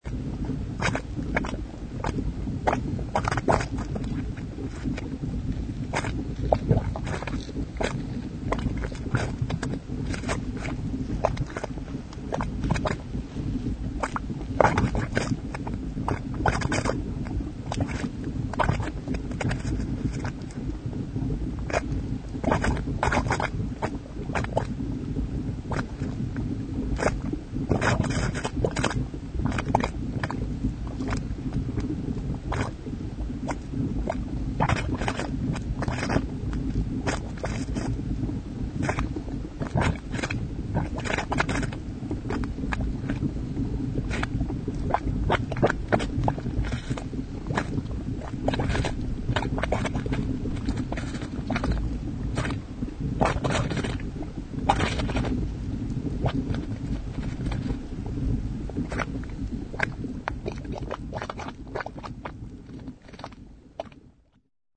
Stereo MP3 Pro sound file of Pipeline Meadow's mud pot (playable using standard MP3 players) - 520K, one minute in length
mudpot03.mp3